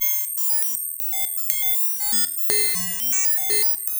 Computer FM.wav